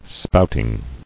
[spout·ing]